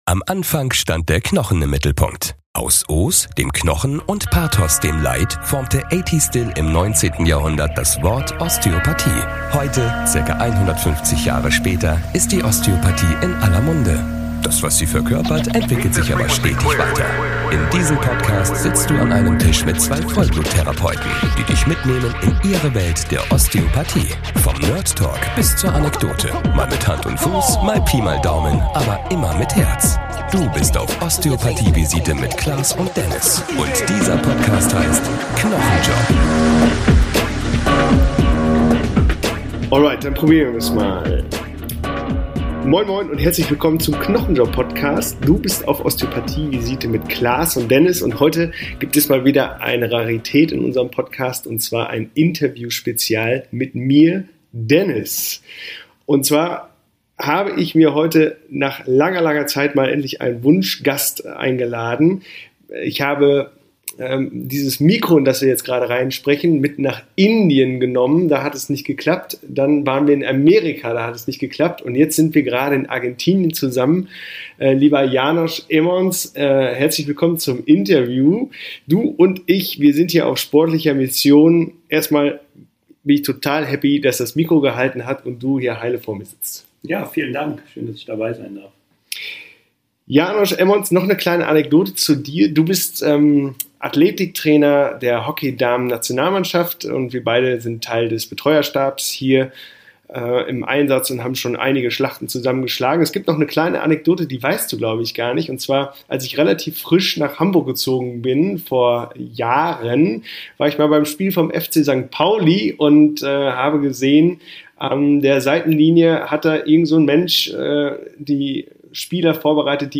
Unter der Sonne Argentiniens sprechen die beiden DANAS-Betreuer über einfache Maßnahmen, die sowohl im Spitzensport, als auch im Amateursport und selbst bei Nicht-Sportler*innen zu schnellerer Regeneration und nachhaltiger Gesundheit führen können. Eistonne, Waldbaden, Breathwork, Supplemente, Schlaf und viele weitere Stellschrauben, an denen wir alle justieren können und ein Füllhorn an Hausaufgaben-Ideen für alle Patientinnen und Patienten.